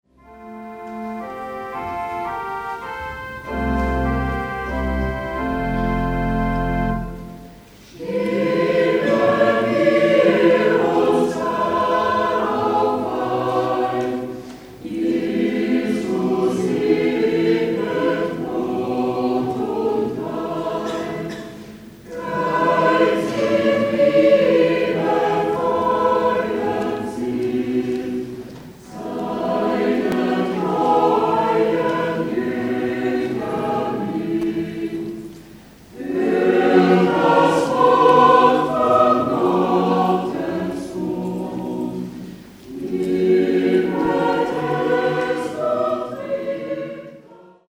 (Live-Aufnahmen)